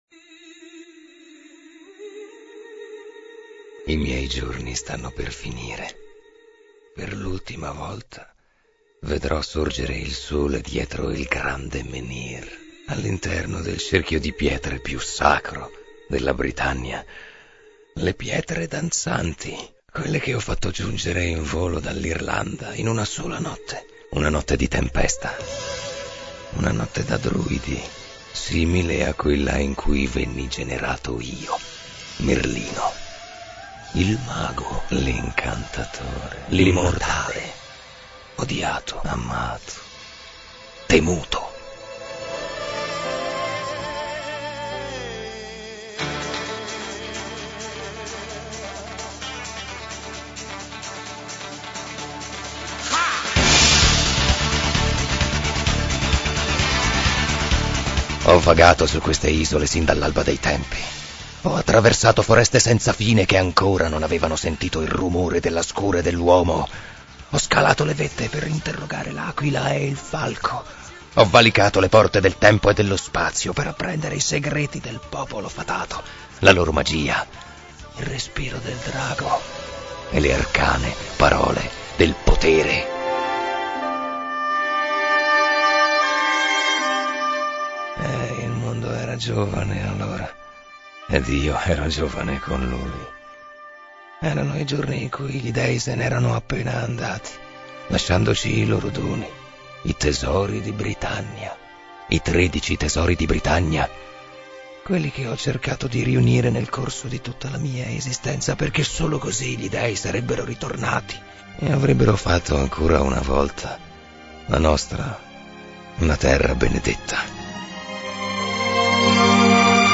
Audio da trasmissione radiofonica